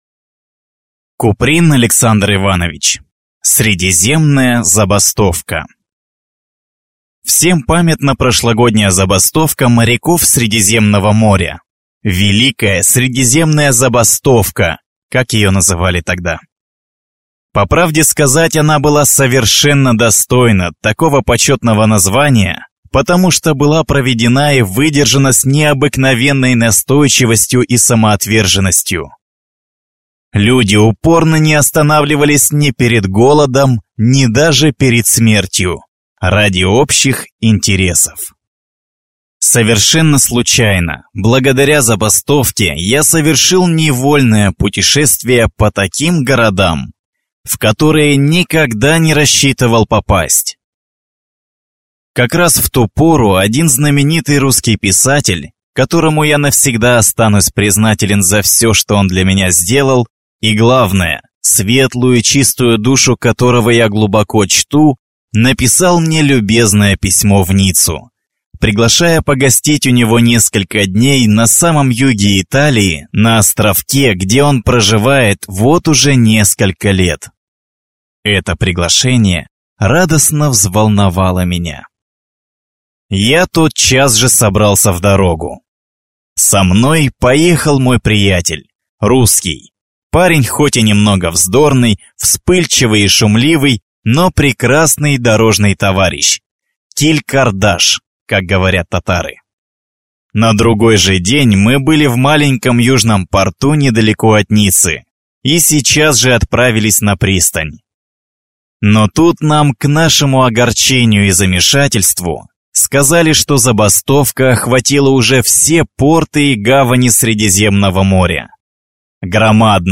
Аудиокнига Средиземная забастовка